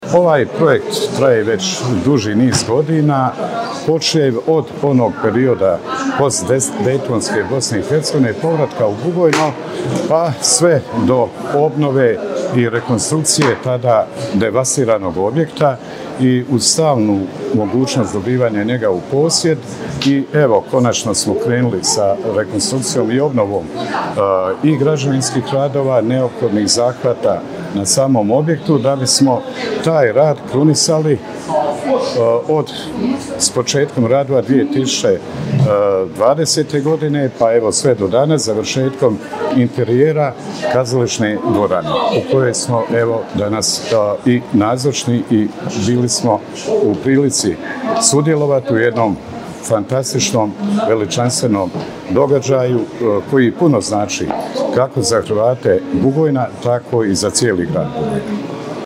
Otvorena je obnovljena kino-kazališna dvorana u Hrvatskom domu.